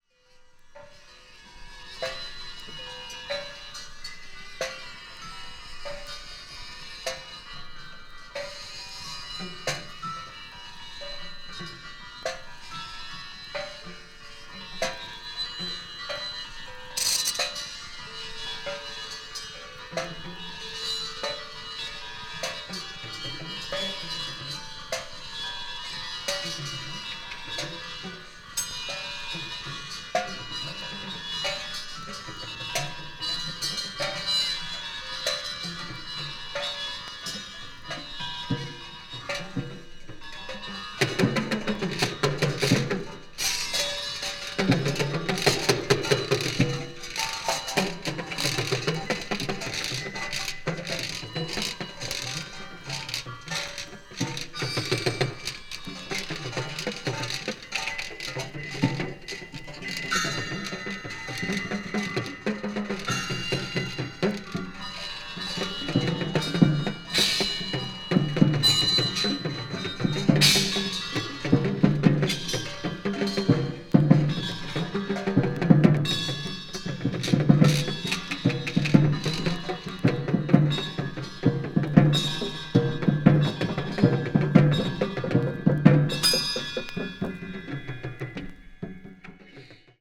avant-jazz   ethnic jazz   free improvisation   free jazz